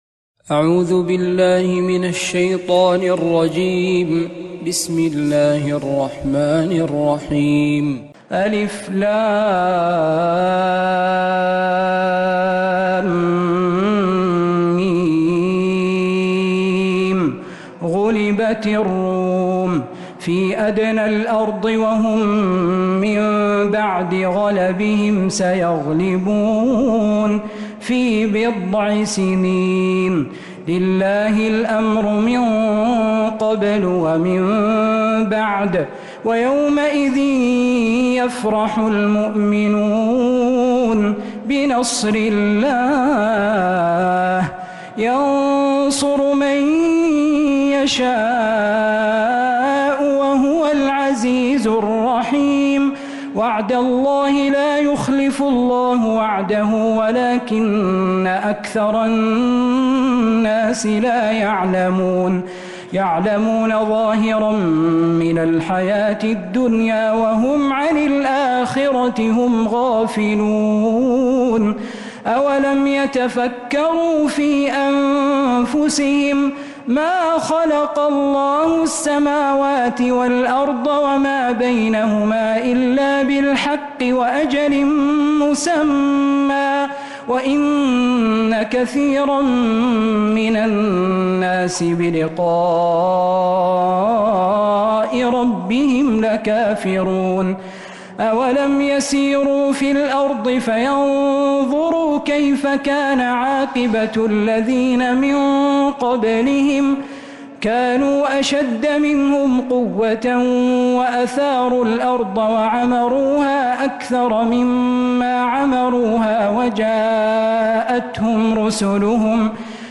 من تراويح و فجر الحرم النبوي